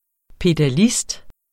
Udtale [ pedaˈlisd ]